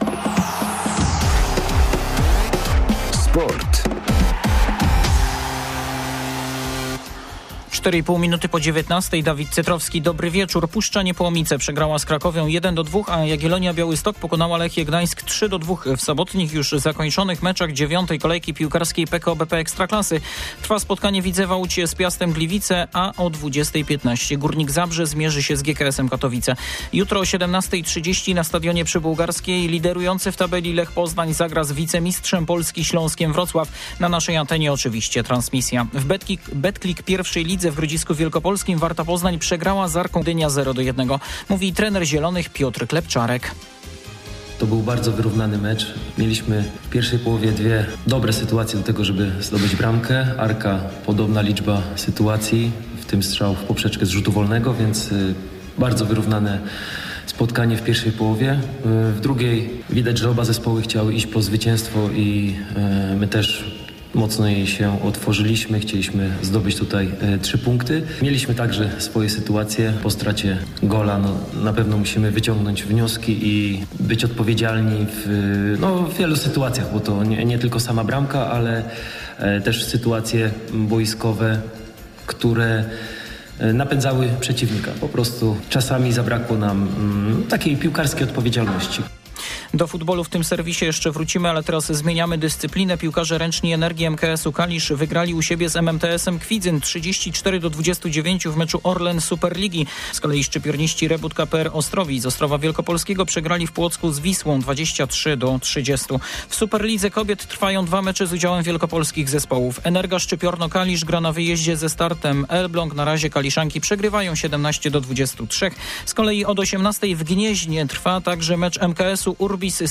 21.09.2024 SERWIS SPORTOWY GODZ. 19:05
W sobotnim serwisie sporo o futbolu - zarówno męskim, jak i kobiecym. Jest także relacja live z meczu piłkarek ręcznych z Gniezna.